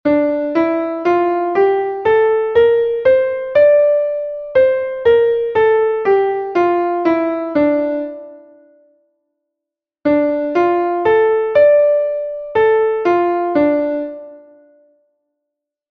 Entoación a capella
Escala e arpexio:
escala_arpegio_re_menor.mp3